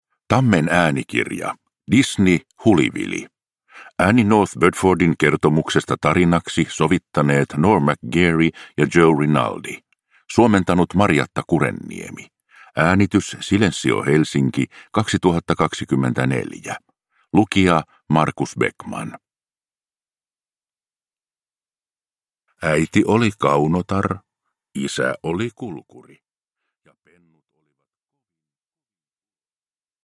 Disney. Hulivili – Ljudbok